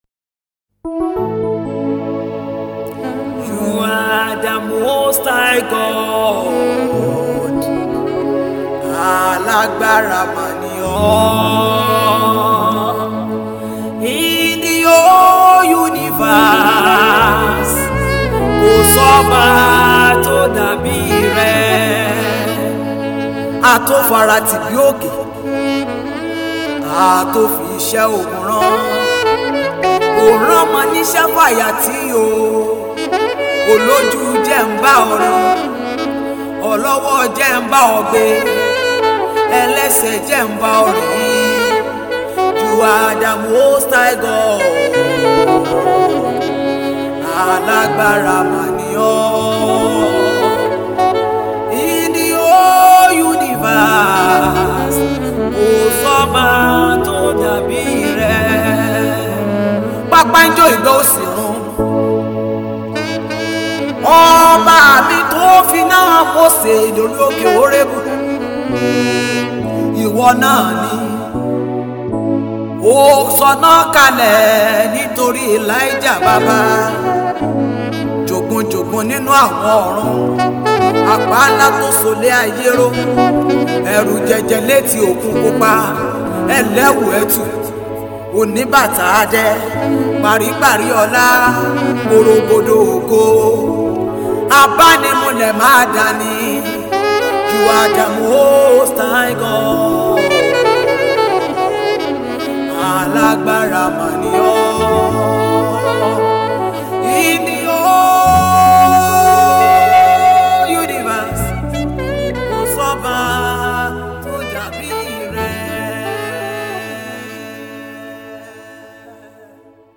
praise/worship